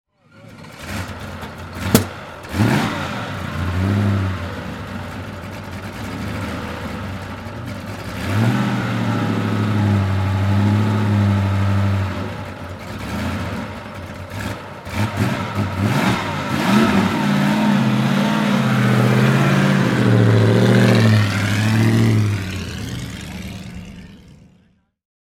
GP Mutschellen 2012 - es muss nicht immer Goodwood sein (Veranstaltungen)
Maserati 8CM (1933) - Starten und Wegfahrt
Maserati_8CM.mp3